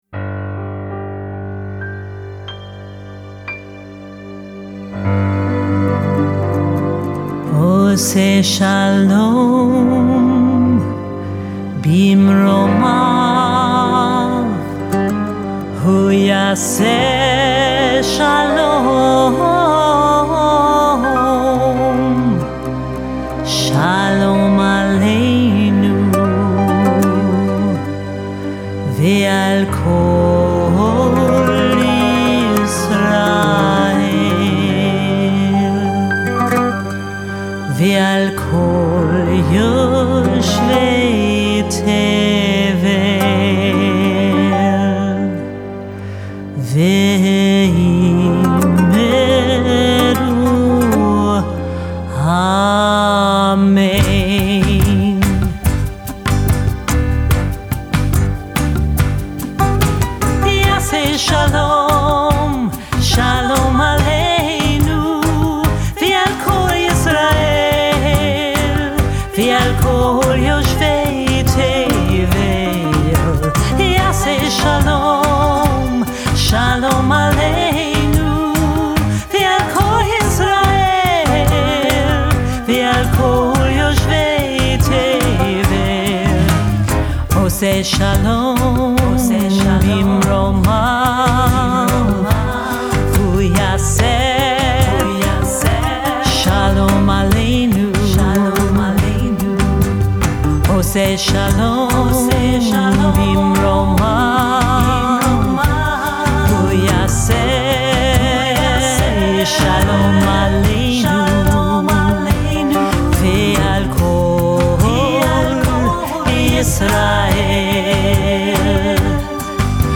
Composer & Cantorial Soloist